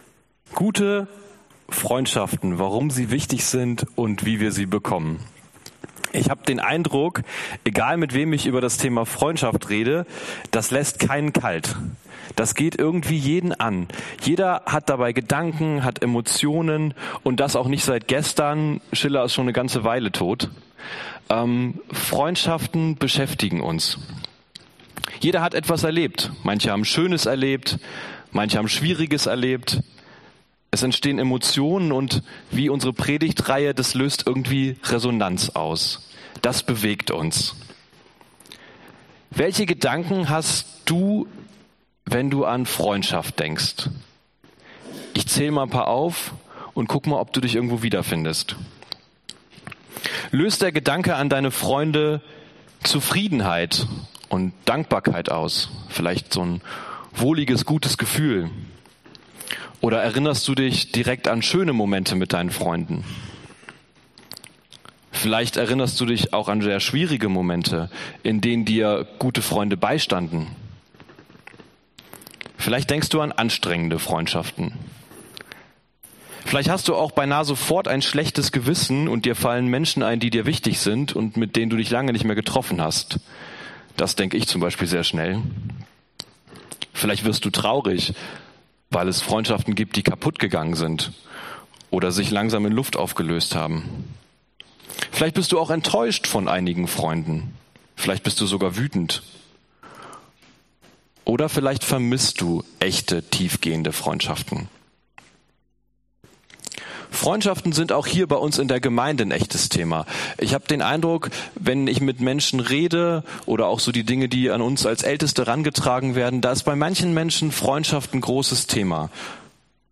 Serie: RESONANZ - wenn wir widerhallen Dienstart: Predigt Themen: Freundschaft